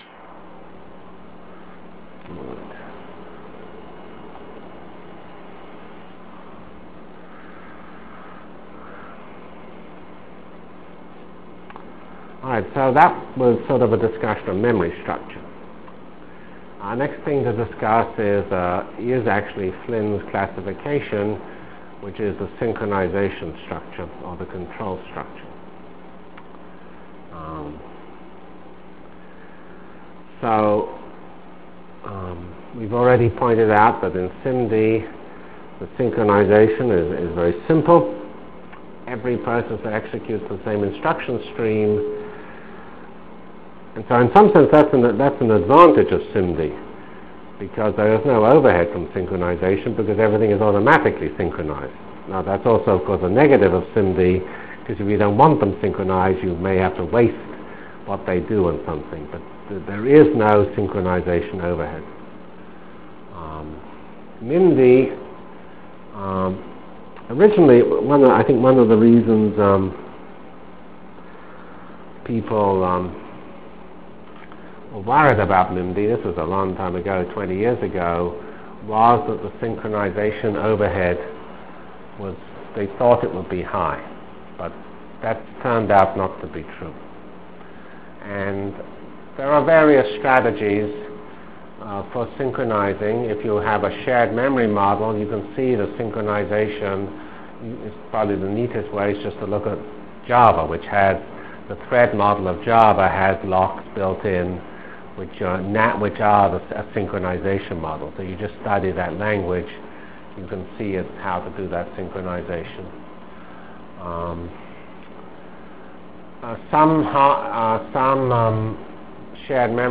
From CPS615-Lecture on Performance(end) and Computer Technologies(start) Delivered Lectures of CPS615 Basic Simulation Track for Computational Science -- 10 September 96.